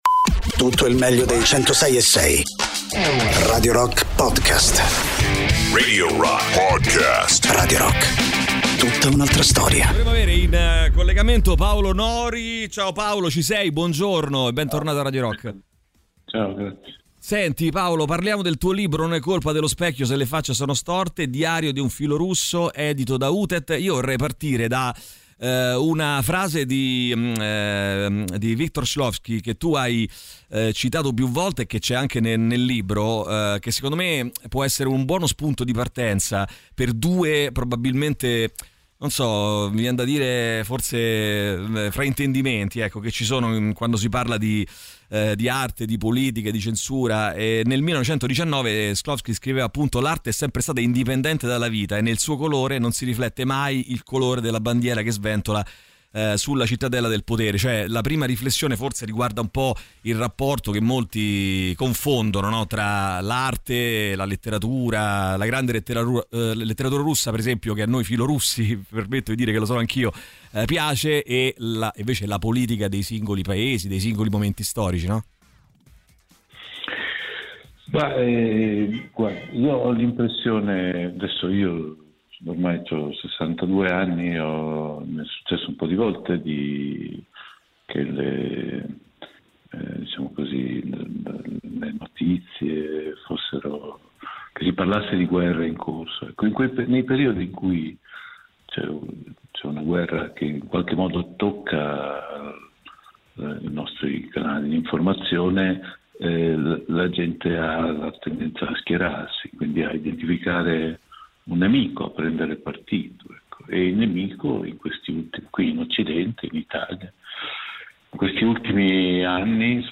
Interviste: Paolo Nori (21-10-25)